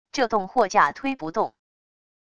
这栋货架推不动wav音频生成系统WAV Audio Player